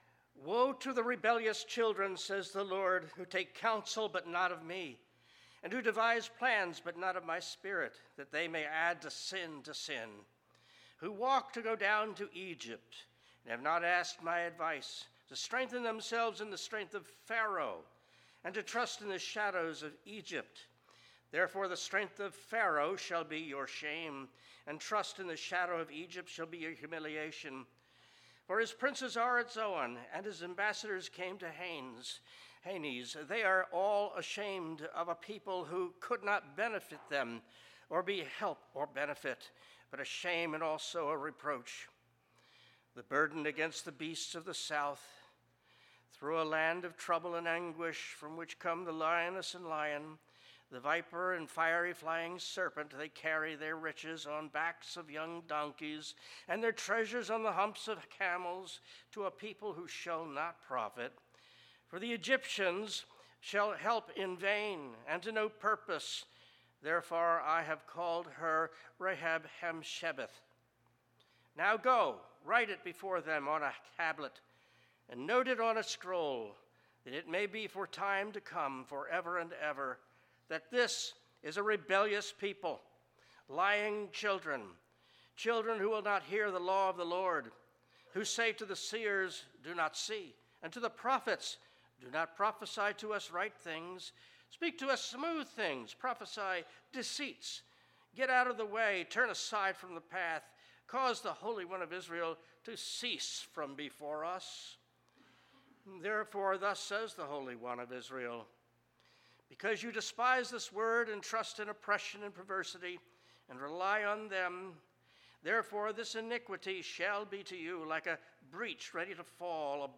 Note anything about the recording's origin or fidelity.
Passage: Isaiah 30:1-22 Service Type: Worship Service « The Abundant Life–What Is It Really Like?